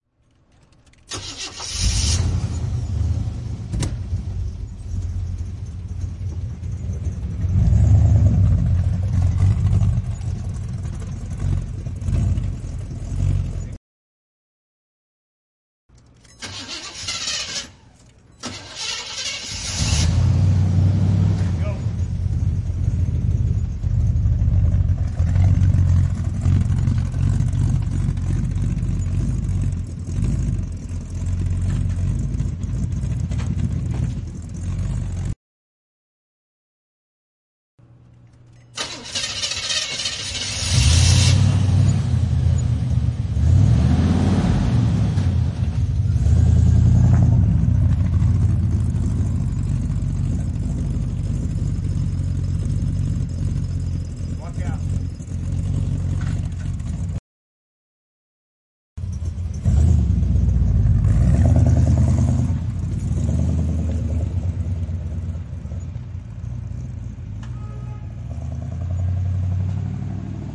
随机的" 汽车性能汽车ext int 启动怠速与转速和拉远
描述：汽车性能汽车ext int开始闲置与转速和拉开
Tag: 转速 性能 启动 怠速 汽车 INT